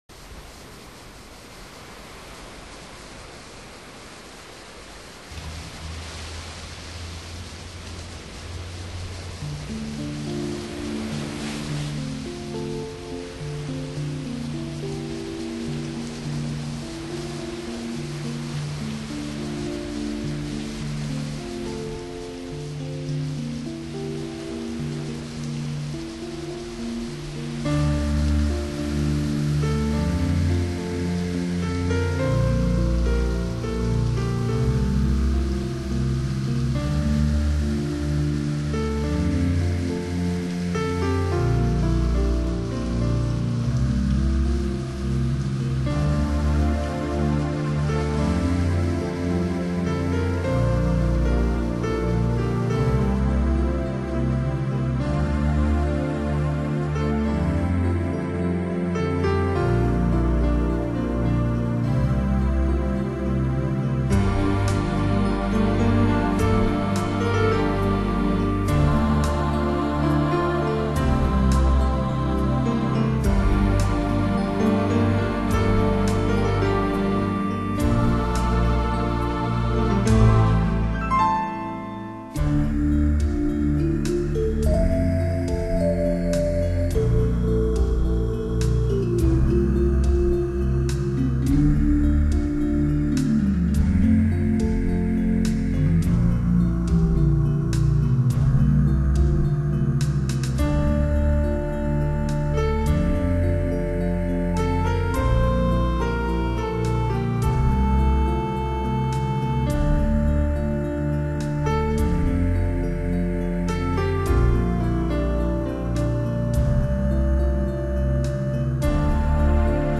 炽热大地的依恋与渴望，弃满海的意象的乐曲